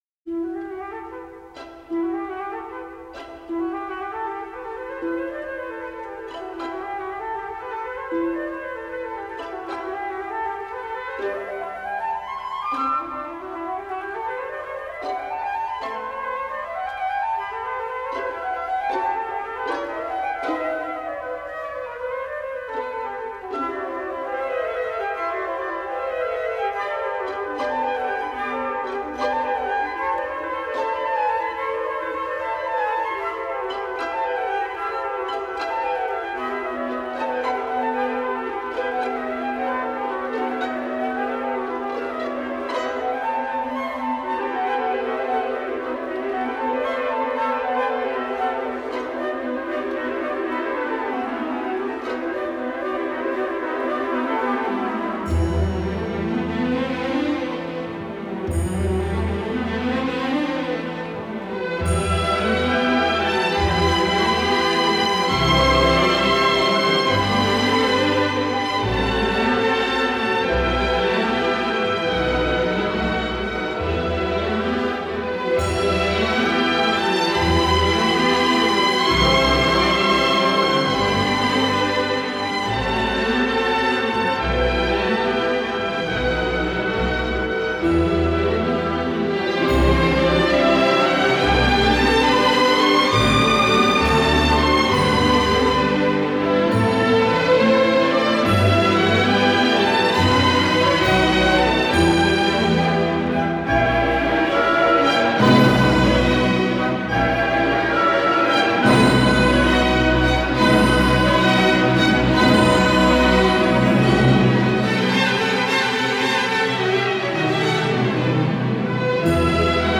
Vltava je �eška rijeka koja se skladatelja duboko dojmila. Skladatelj je razli�itim melodijama, sporim i brzim tempom, raznolikim zvukovnim bojama pokušao do�arati opis njezina toka i �ivot na njezinim obalama.